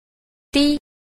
b. 低 – dī – đê